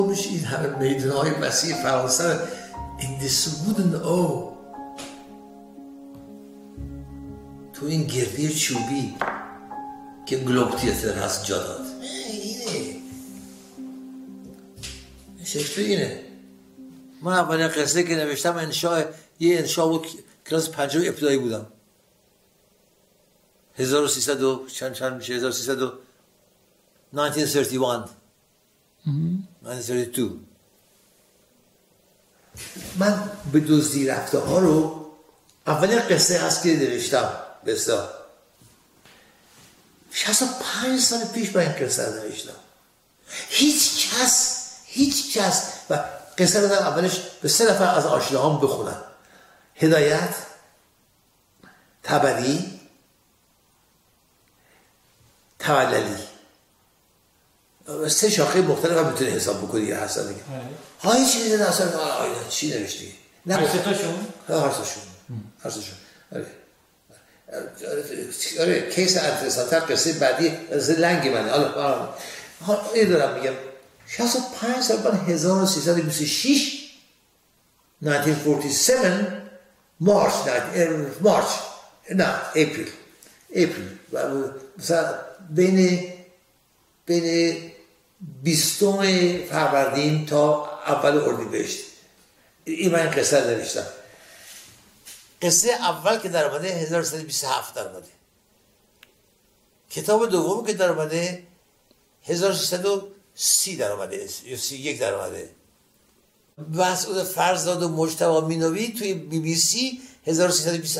Mosahebeh.mp3